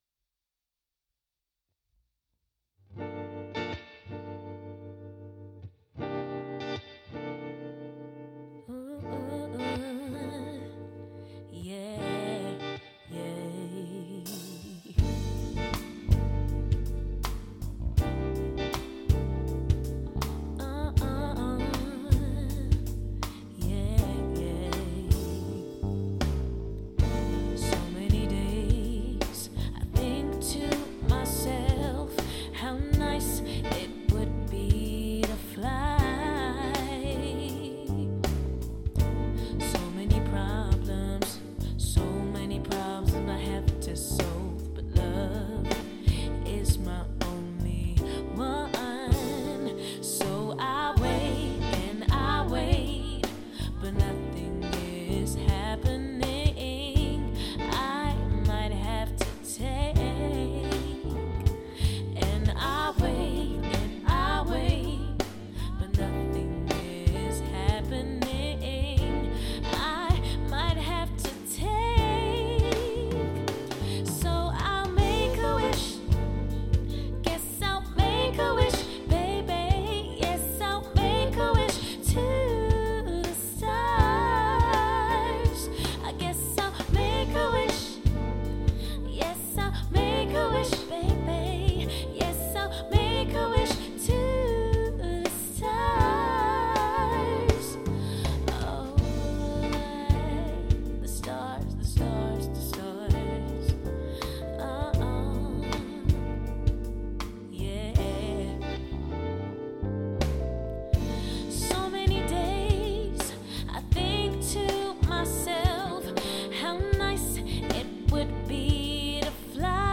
soulful pop and R&B influenced songs
As the band developed we started to record demos of the songs we had been writing together in my flat in Kilburn.